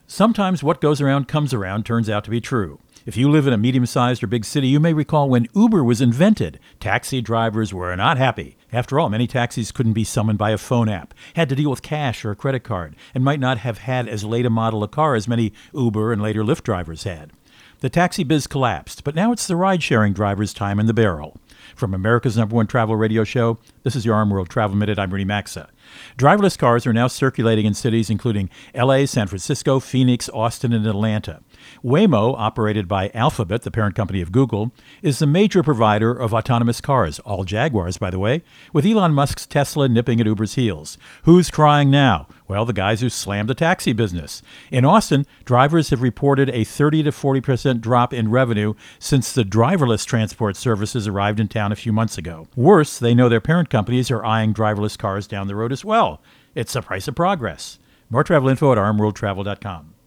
Co-Host Rudy Maxa | Taxis being replaced by Ride Share, and now…